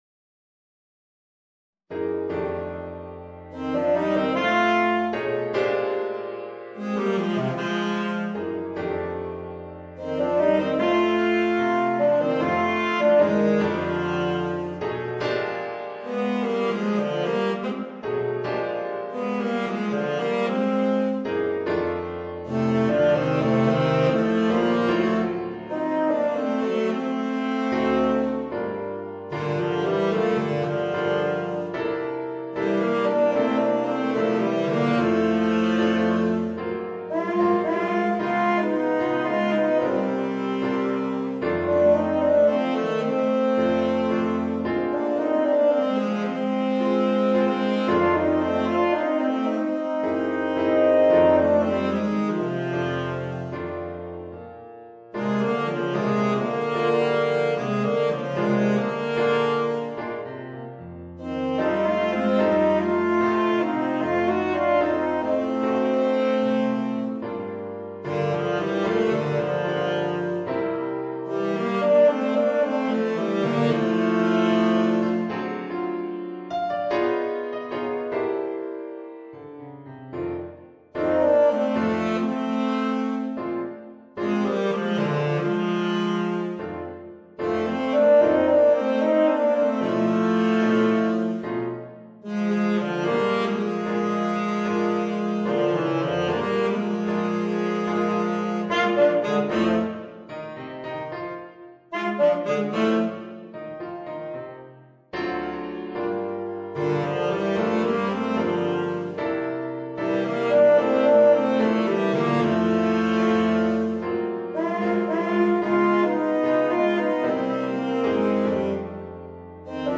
Voicing: 2 Tenor Saxophones and Piano